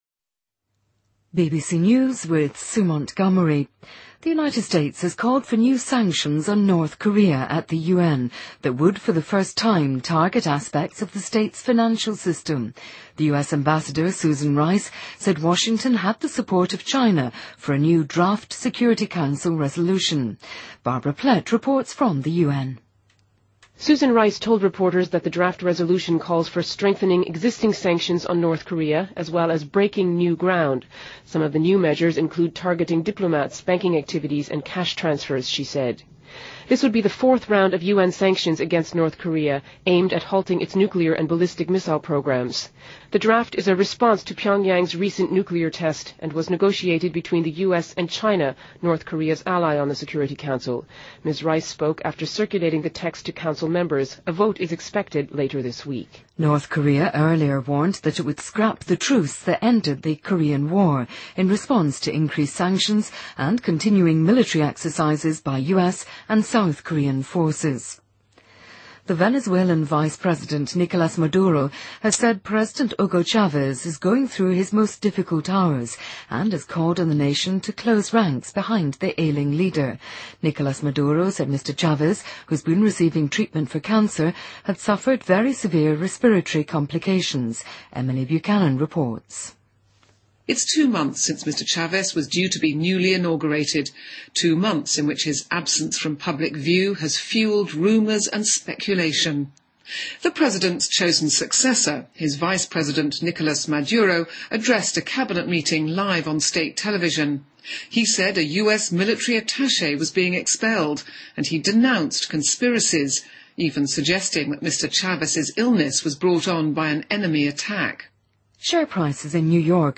BBC news,2013-03-06